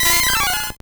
Cri de Férosinge dans Pokémon Or et Argent.